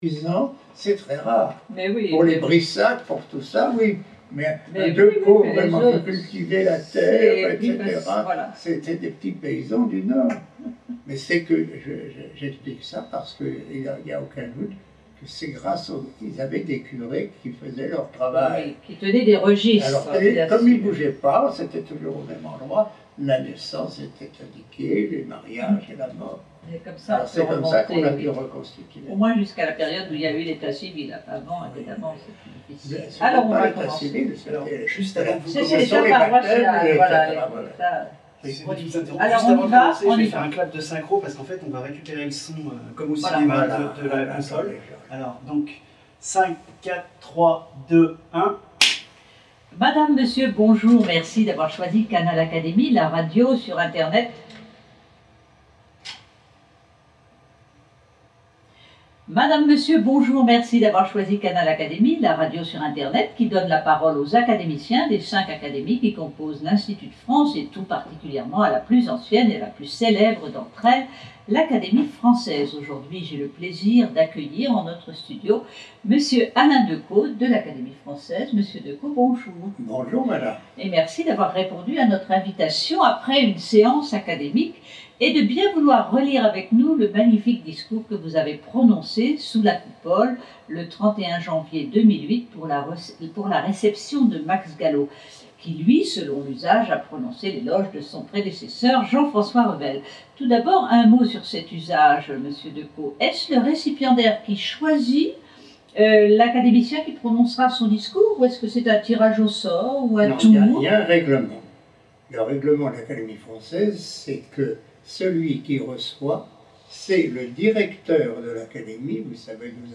Sous la Coupole, le jeudi 31 janvier 2008, Alain Decaux prononçait le discours de réception de Max Gallo.